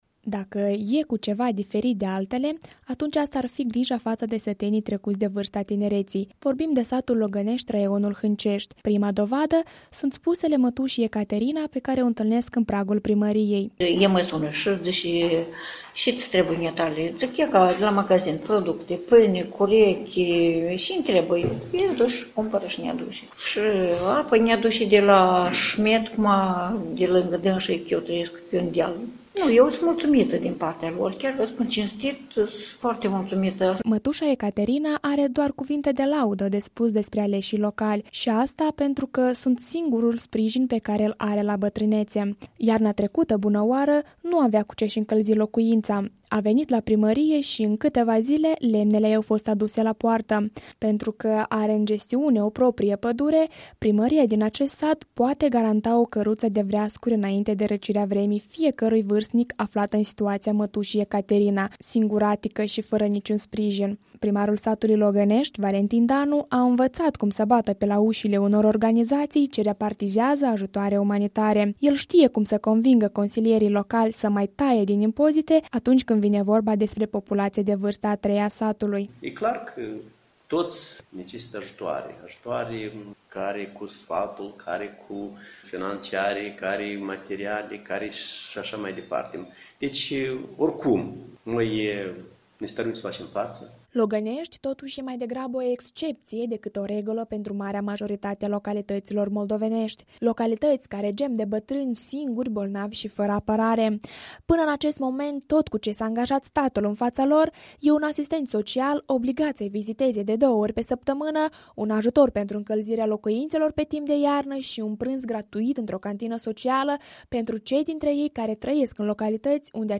Acest reportaj a fost produs cu sprijinul programului „Consolidarea Presei Independente și a Educației Mediatice în Moldova” al IREX Europe.